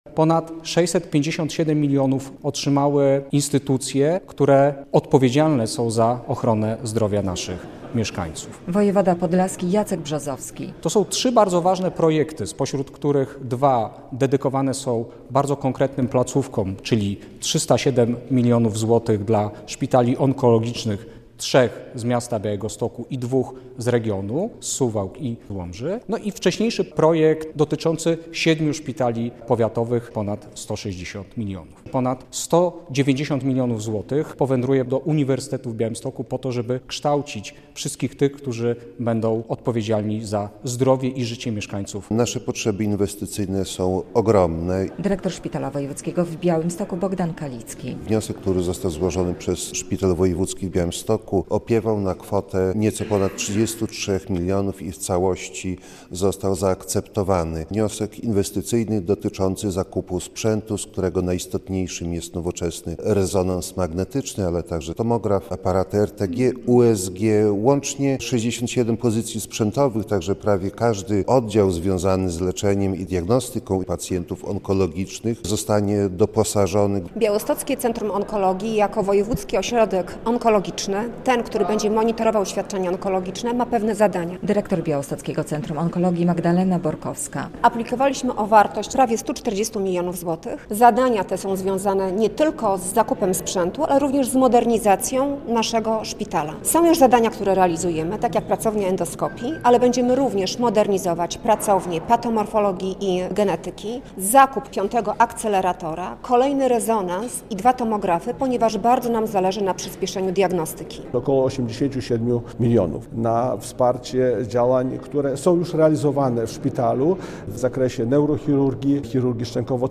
Ponad 657 mln zł z Krajowego Planu Odbudowy trafi łącznie na różne inwestycje w poprawę ochrony zdrowia w Podlaskiem – poinformowały we wtorek na konferencji prasowej w Białymstoku władze województwa.
relacja